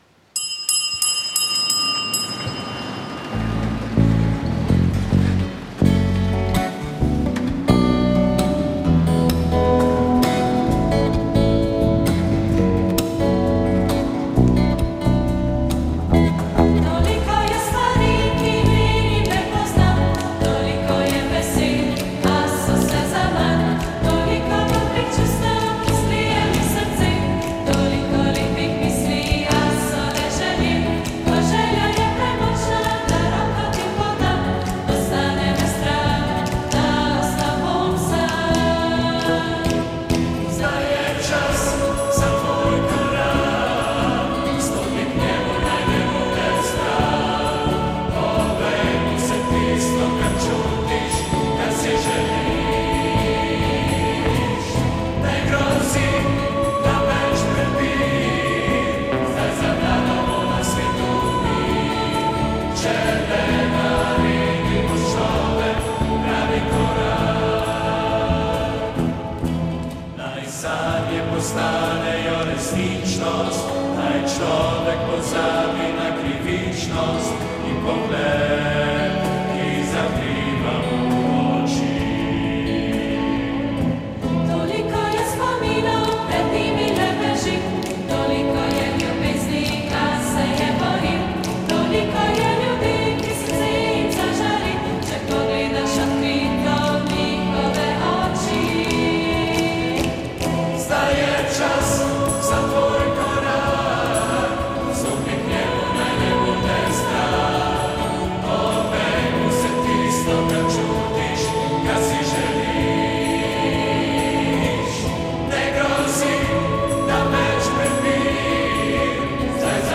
Sveta maša
Sv. maša iz cerkve sv. Mihaela v Grosuplju 20. 4.